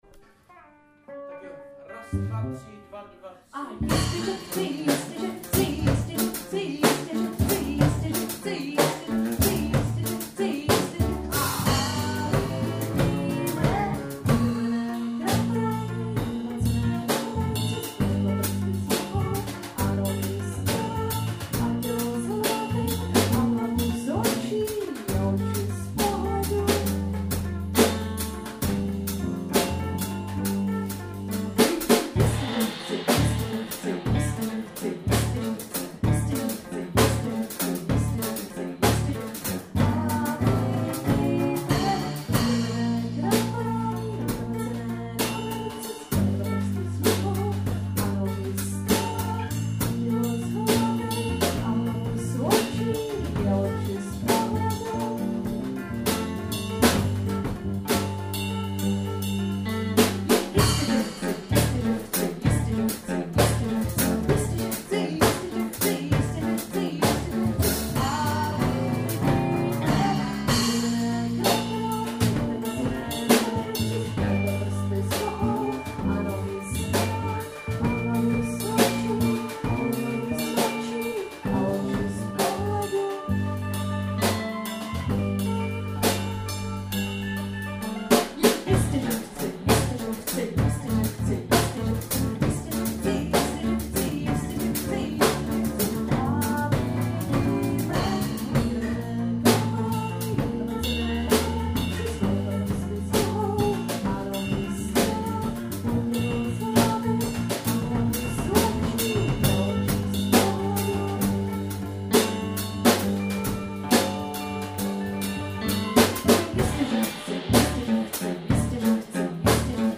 STE-136_jisteze_kolecko_napady_kyt_bas.mp3